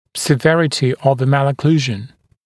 [sɪ’verətɪ əv ə ˌmælə’kluːʒn][си’вэрэти ов э ˌмэлэ’клу:жн]тяжесть аномалии прикуса, выраженнсть аномалии прикуса